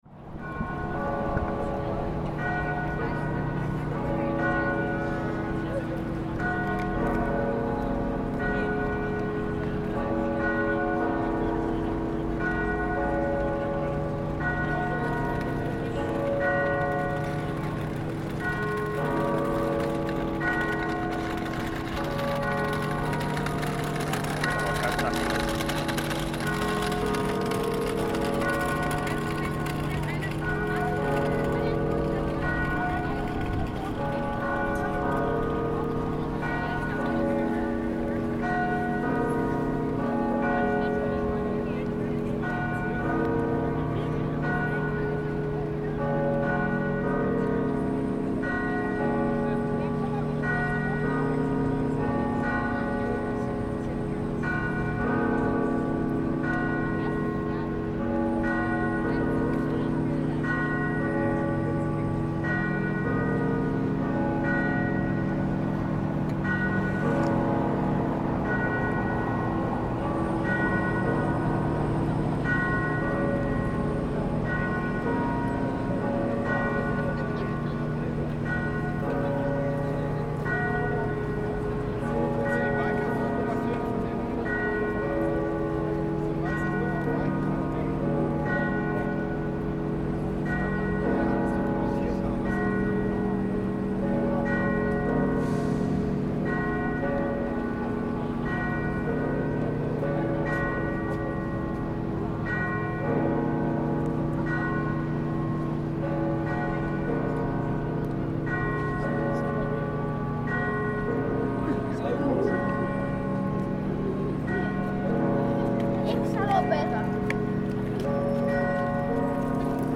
The bells ring out across Senate Square in Helsinki, Finland, to mark midday - we can also hear the general hustle and ambience of a central square in the middle of a typical day.